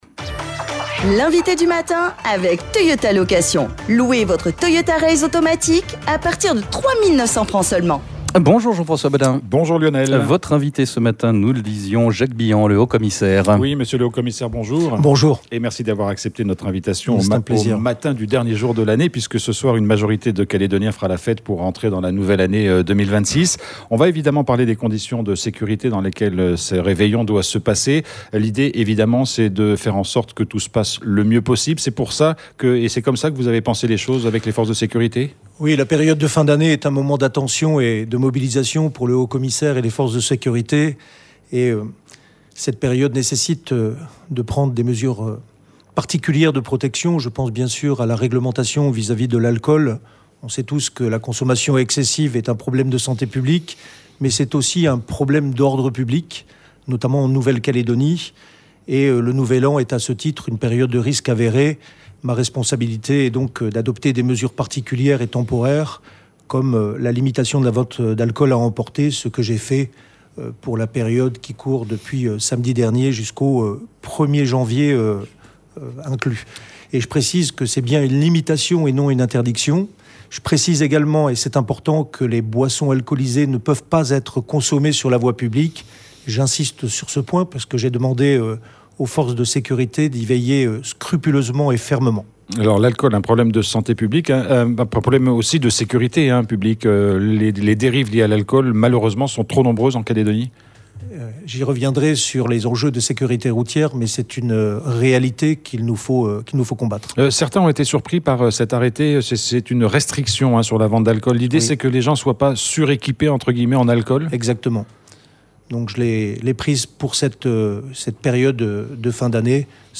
Jacques Billant, le Haut-Commissaire, est notre invité.